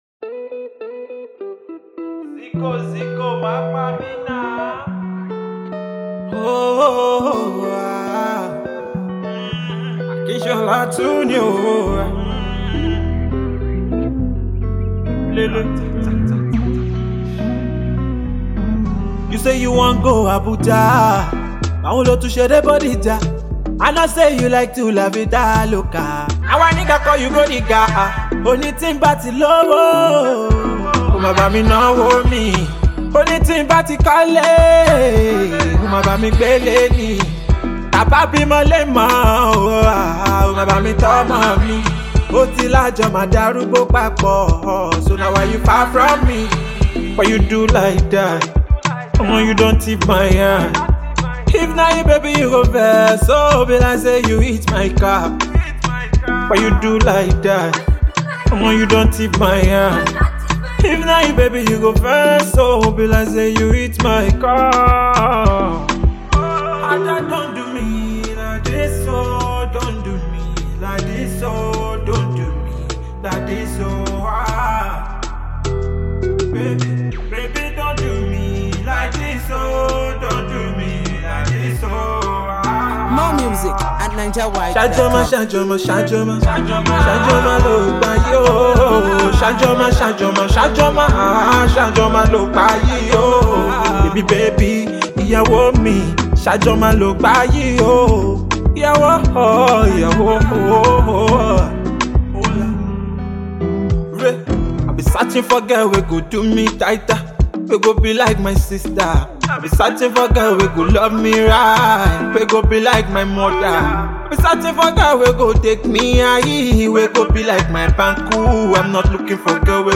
Nigerian Afropop
smooth and trendy tune